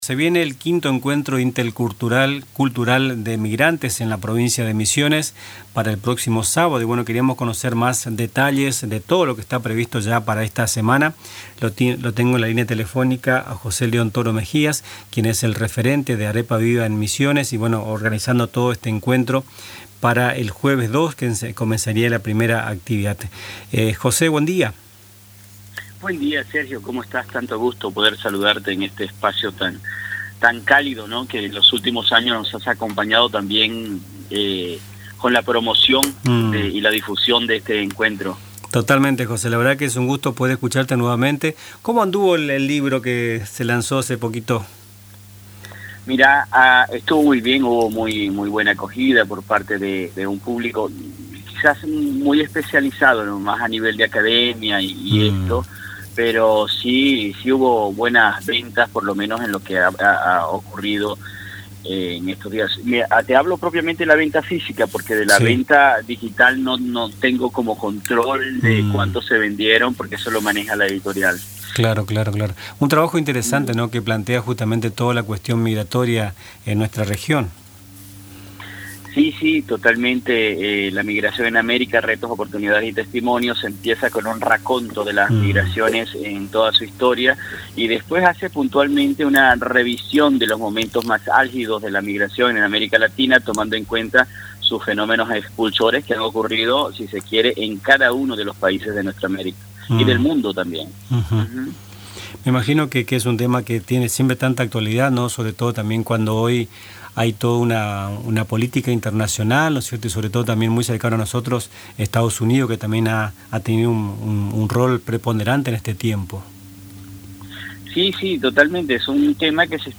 En diálogo con Nuestras Mañanas